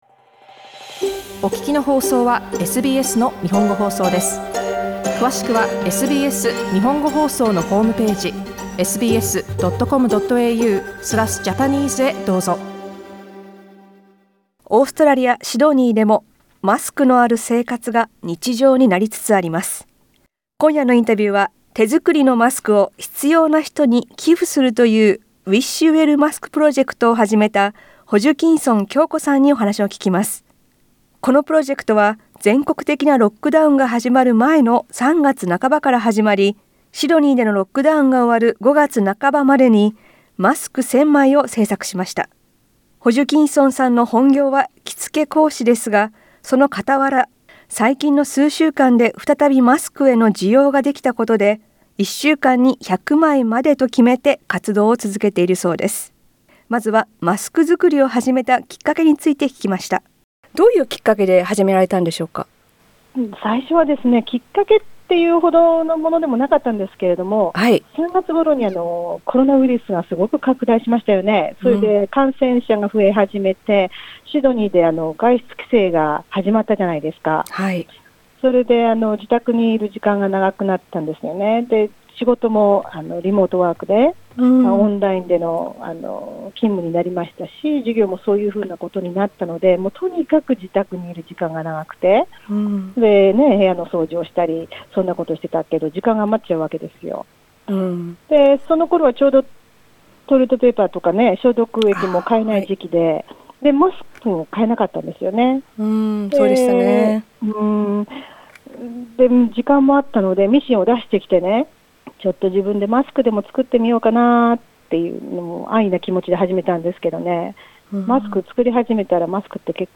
最近はシドニーでもマスク需要が再燃し、口コミやリピーターからの依頼が増えたこともあって、マスク作りを再開しています。 インタビューでは、マスク作りを始めたきっかけや活動の広がり、またマスクを受け取った方の反応などについて聞きました。